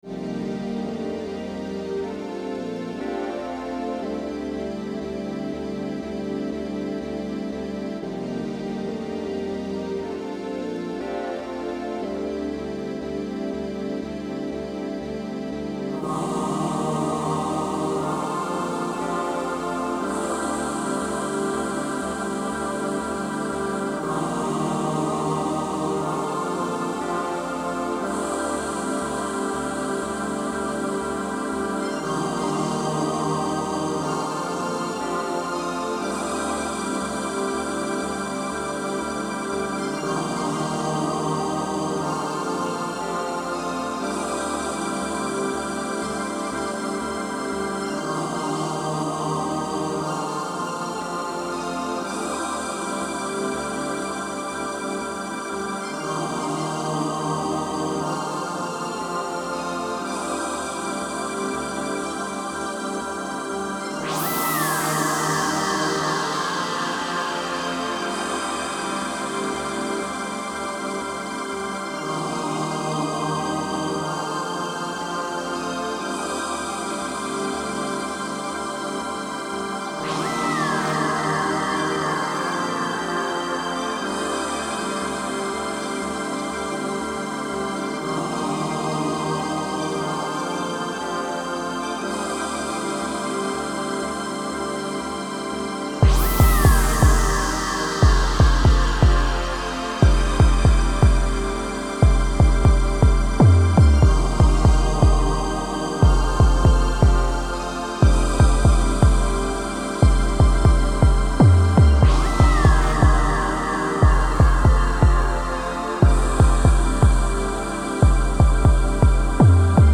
Genre: Chillout, Downtempo, Ambient.